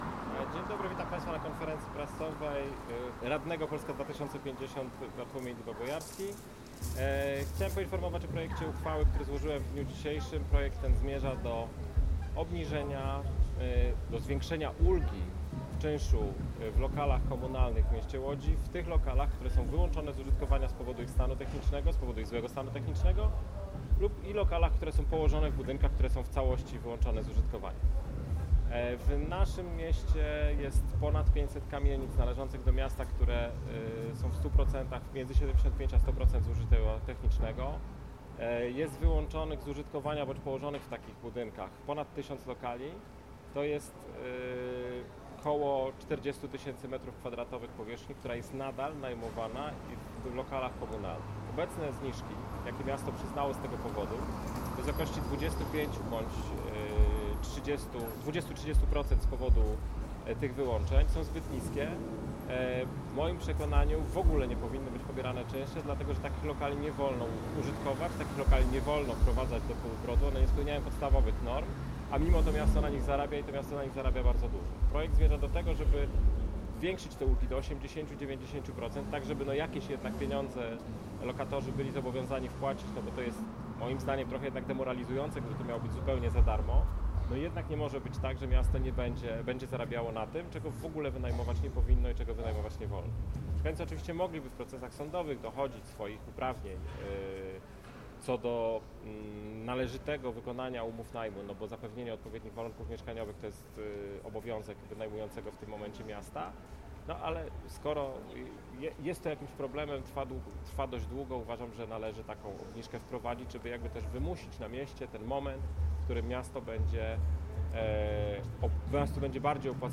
Posłuchaj całej konferencji prasowej: https